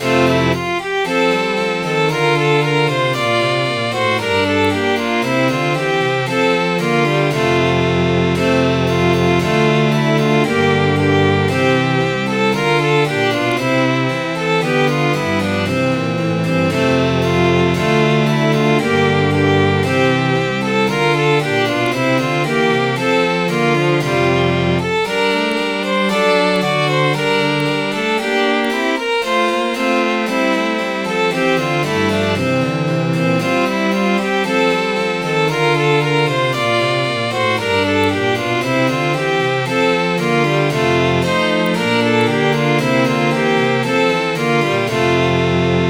Midi File, Lyrics and Information to Sweet is the Budding Spring of Love